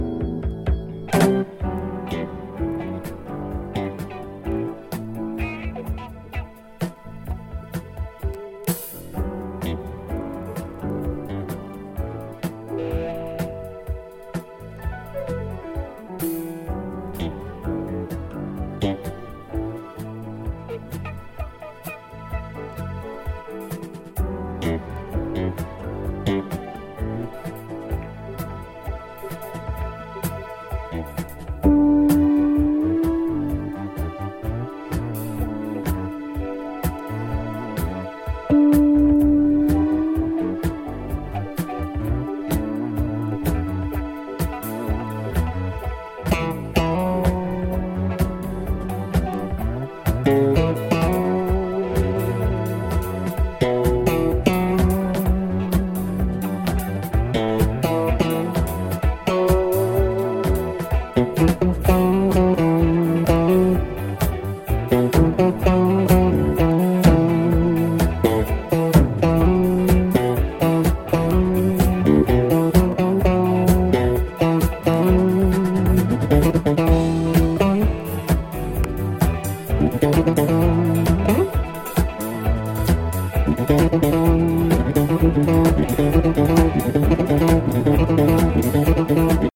a jazz funk cut time stretched for the dancefloor.